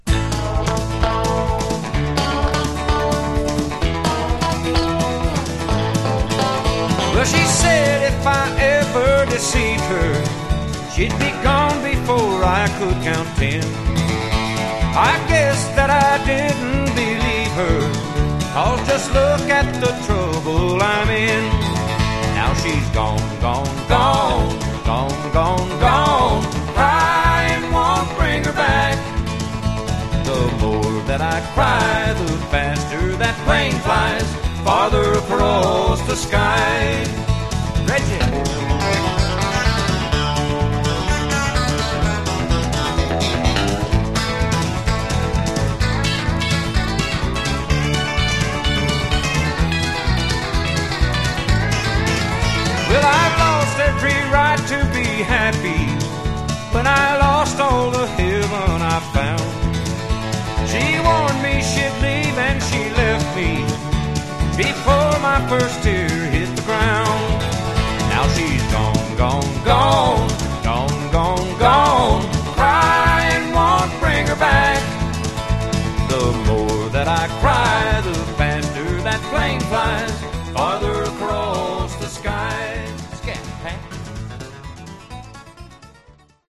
Genre: Rockabilly/Retro
a dynamite uptempo Rockabilly romp